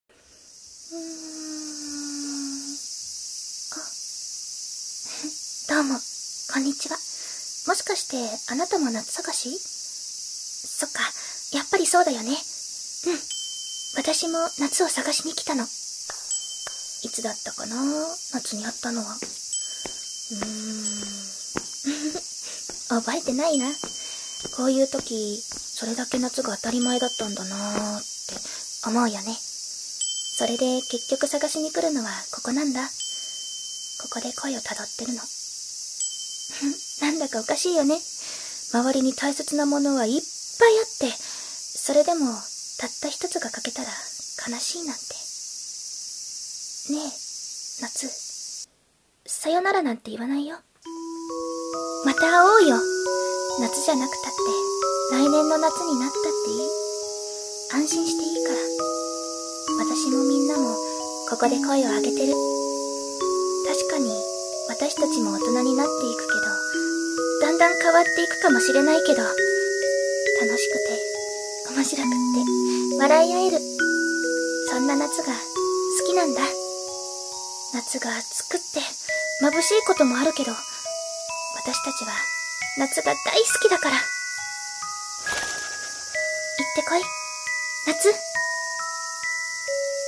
【一人声劇】なつくてあついなつ